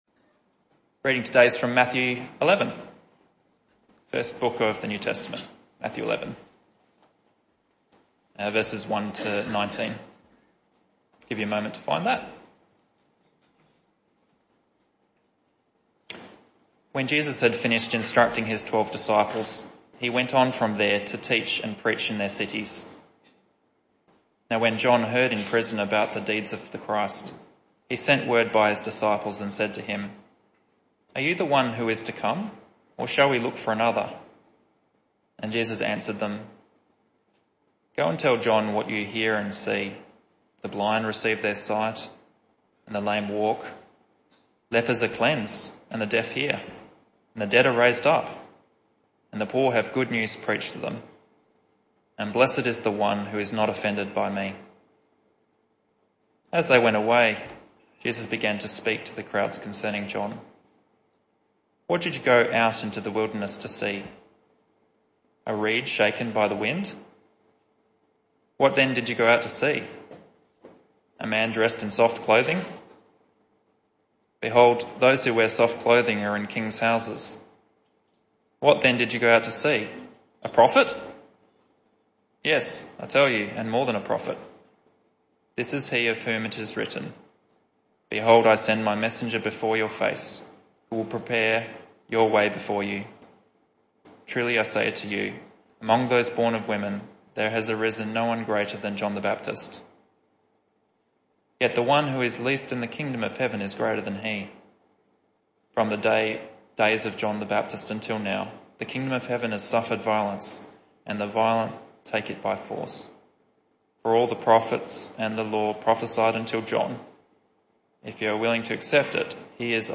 This talk was part of the AM Service series entitled The Message Of Matthew.